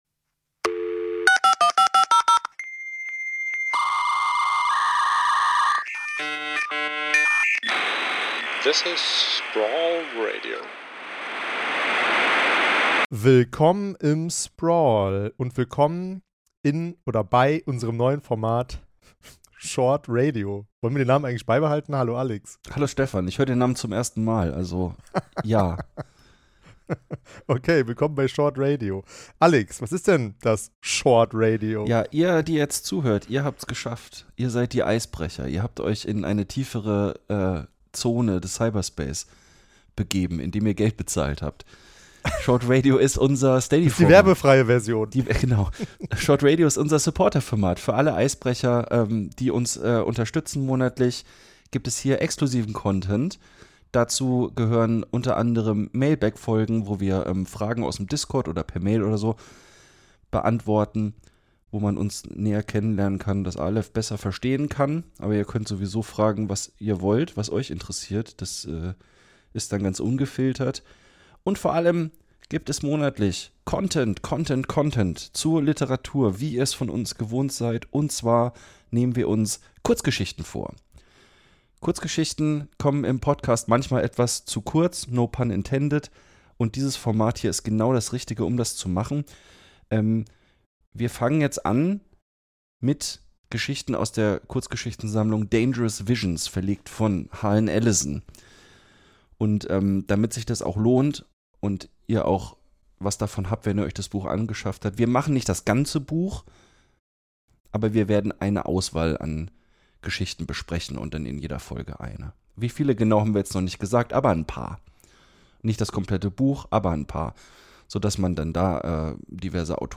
Wir lesen die Kurzgeschichte Flies von Robert Silverberg aus Dangerous Visions (1967). Außerdem ist am Ende der Folge noch Zeit für eure Mailbag-Fragen.